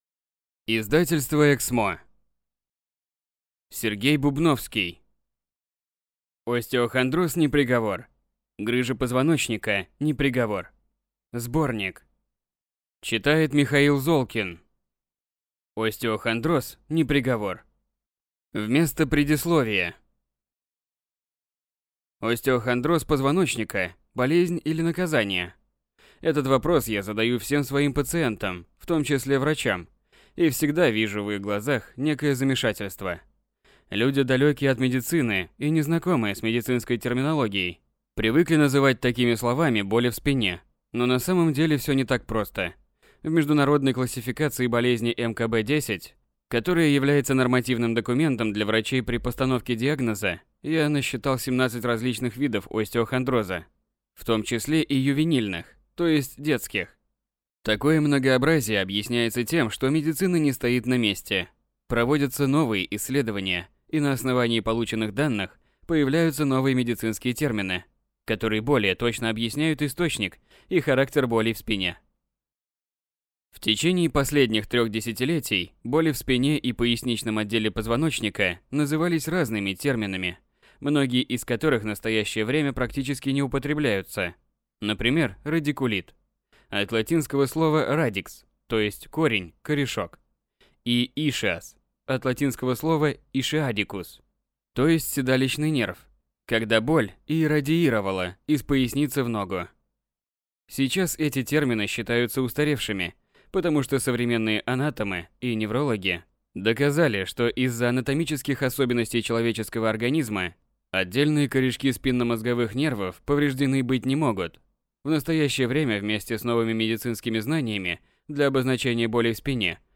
Aудиокнига Остеохондроз – не приговор! Грыжа позвоночника – не приговор! (сборник) Автор Сергей Бубновский